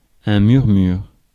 Ääntäminen
Synonyymit rumeur chuchotage Ääntäminen France: IPA: [myʁ.myʁ] Haettu sana löytyi näillä lähdekielillä: ranska Käännös Ääninäyte Substantiivit 1. murmur US 2. chiding 3. babble US Verbit 4. murmur US Suku: m .